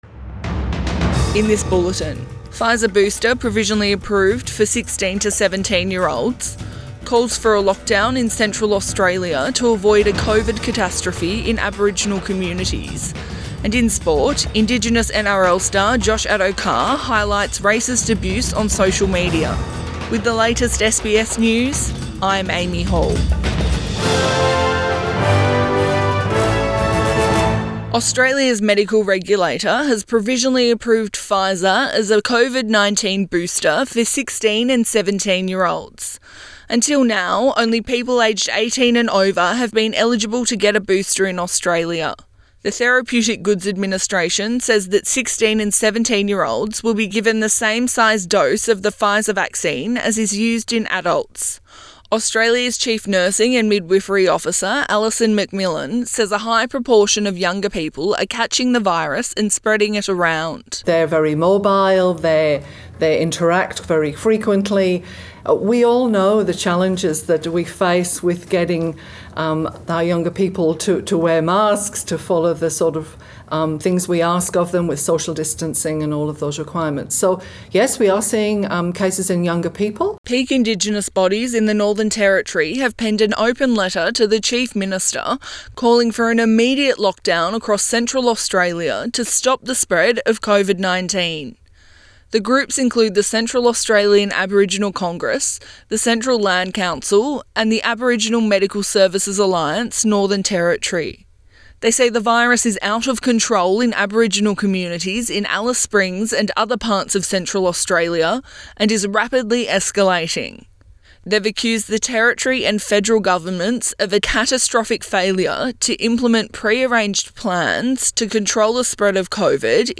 Midday bulletin 28 January 2022